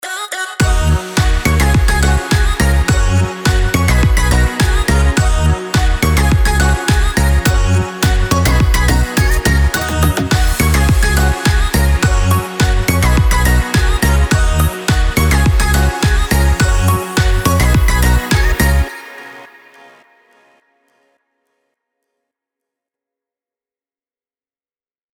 打开一瓶啤酒，这包啤酒将把您的音乐带入一个全新的热带氛围。
-包含完美制作的鼓，FX，合成音，鼓循环，人声和鼓音的样本包